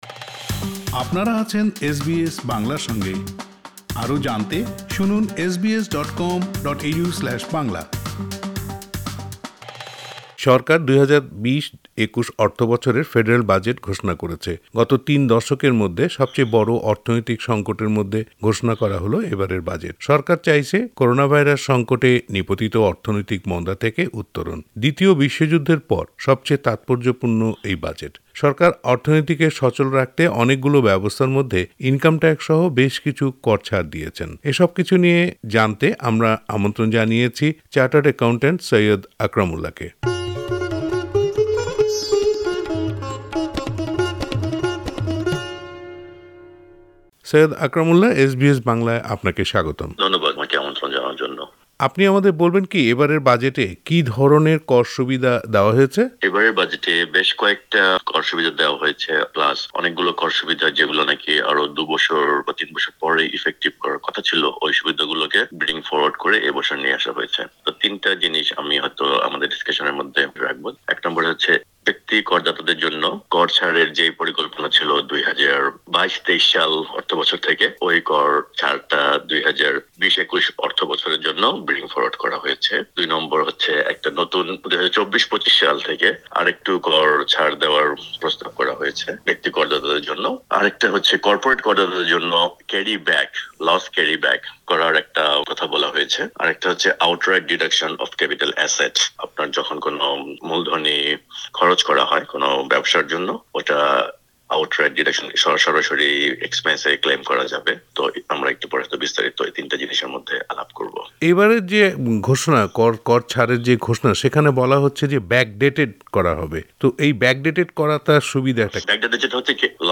এ নিয়ে এস বি এস বাংলার সাথে কথা বলেছেন চাটার্ড একাউন্টেন্ট